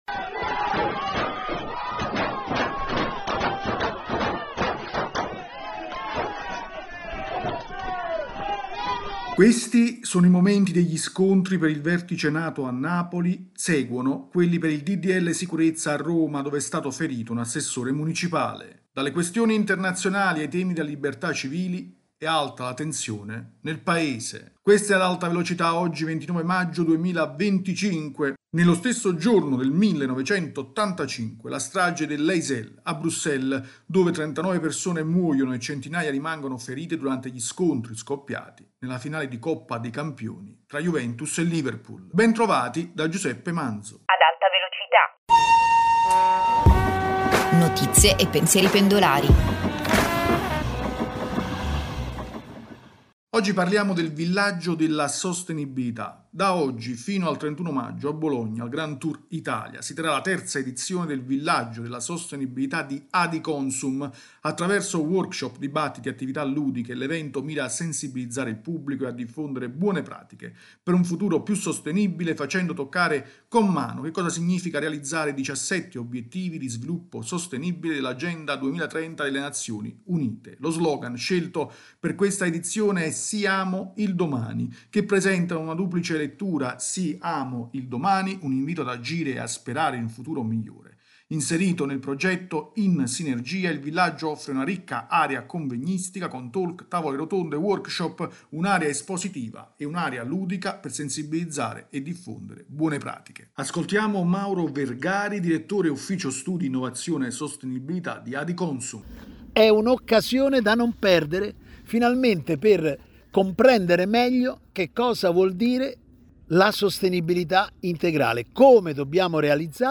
rubrica quotidiana